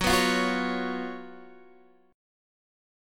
F#M7sus4 chord